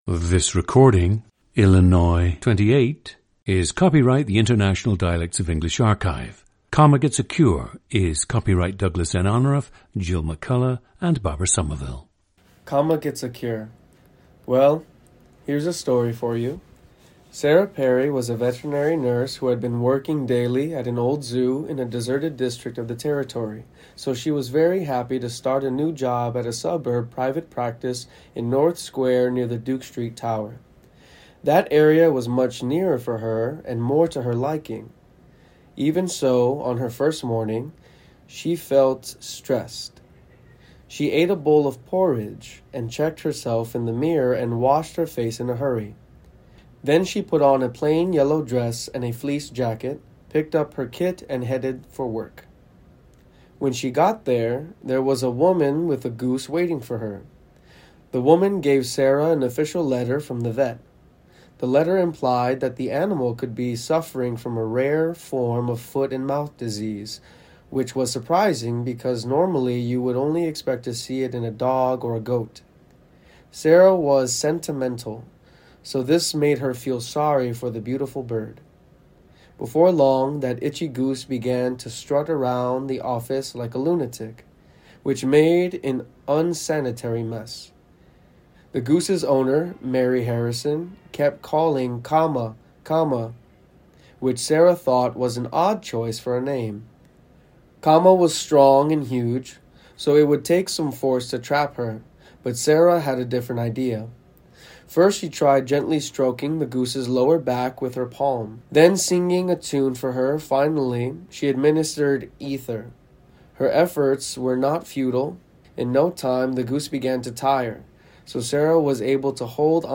GENDER: male
The speaker’s accent while reading has markers reflecting relaxed “General American” pronunciations and oral posture. Note apical L in “fleece” and the SH of “strike” in the scripted reading. Also note shift in oral posture when speaking extemporaneously: Lip corners widen slightly, while jaw and tongue sit a little higher.
• Recordings of accent/dialect speakers from the region you select.
The recordings average four minutes in length and feature both the reading of one of two standard passages, and some unscripted speech.